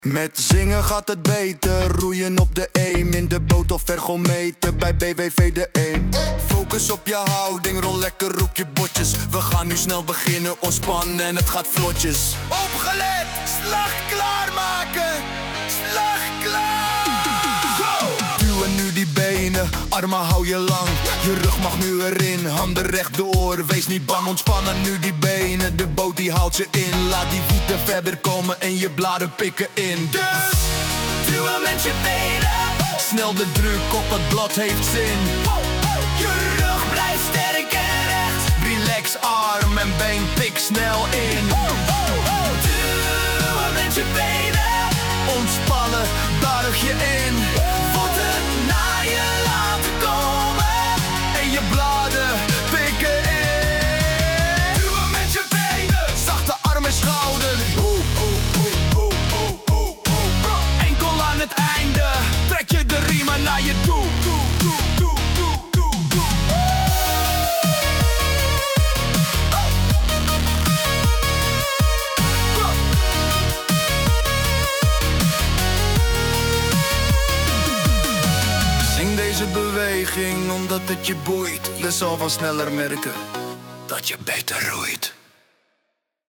– Het roeittechnieklied
TECHNIEK-LIED
catch rap